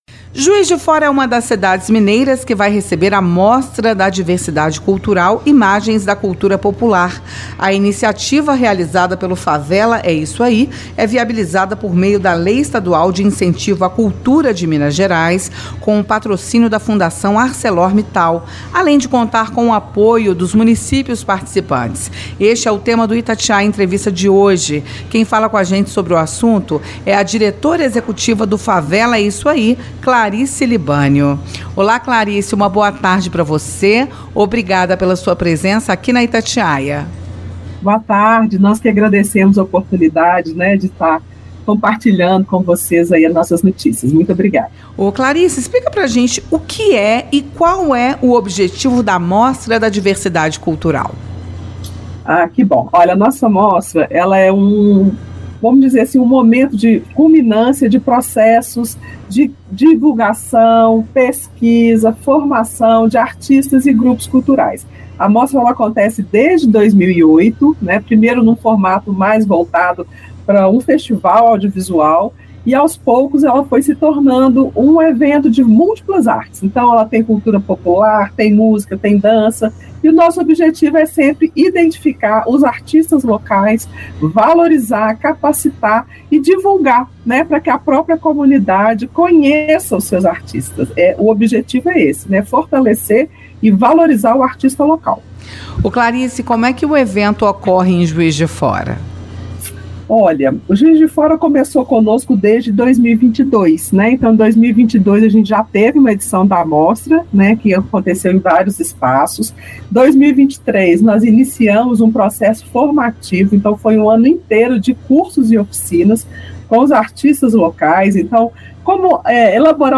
esteve no Itatiaia Entrevista e comenta sobre os objetivos e as expectativas do evento.